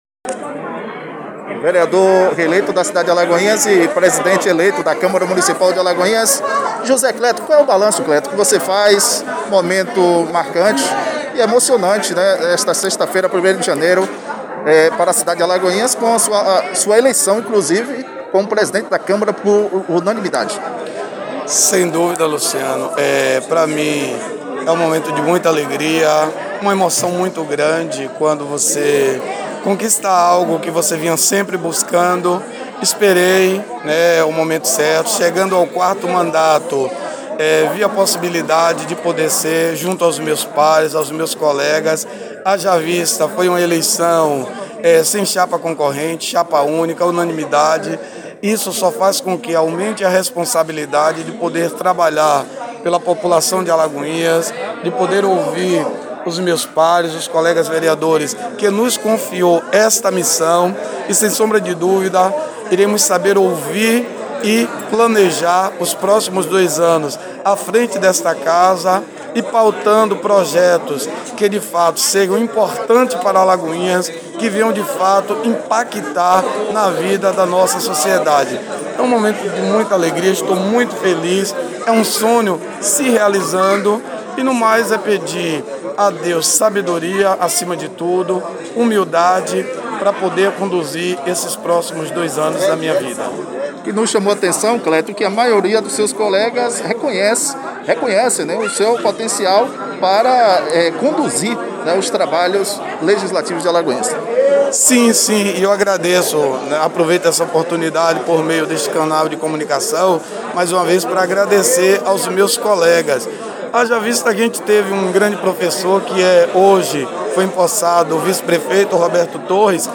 Ouça a entrevista completa e na íntegra do novo presidente da Câmara Municipal de Alagoinhas, vereador José Cleto: